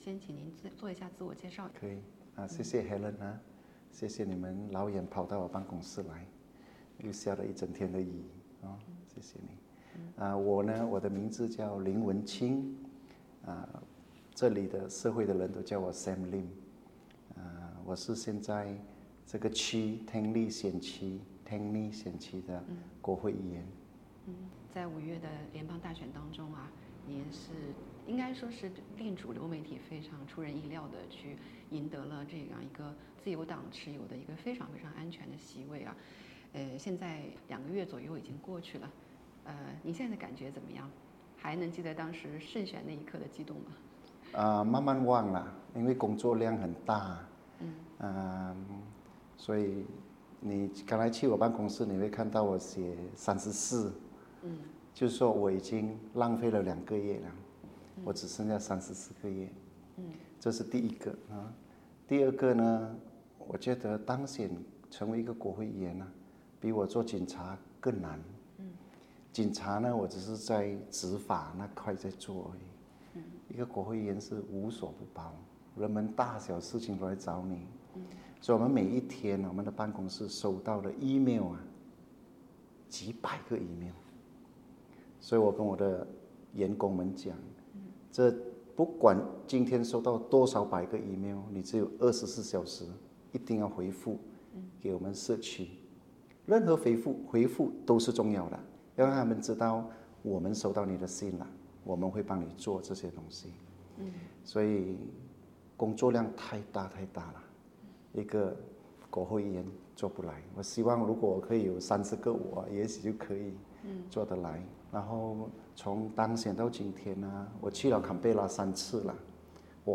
【专访】从林警官到林议员，工党议员林文清如何延续“多元文化的胜利”？